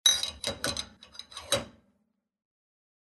Звуки посудомоечной машины
Бокалы для вина и шампанского отправили на мойку